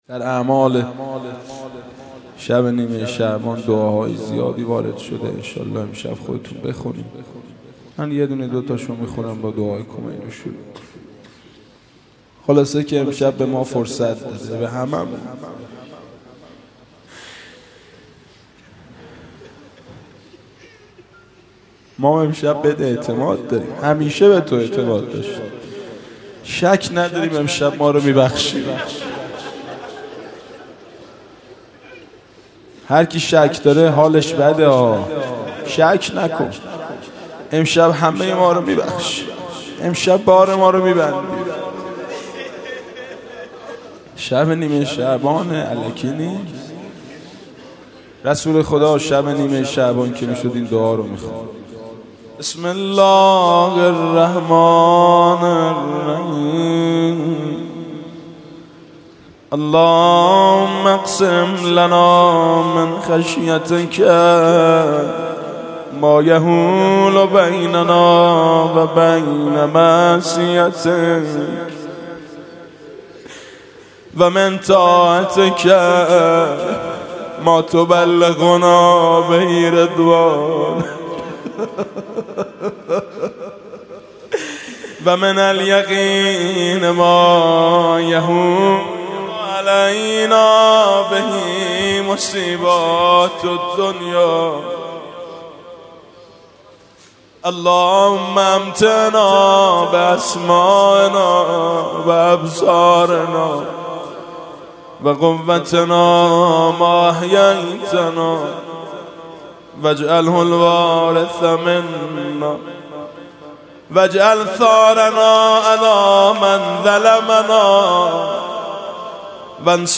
احیا-حسینیه قهرودی ها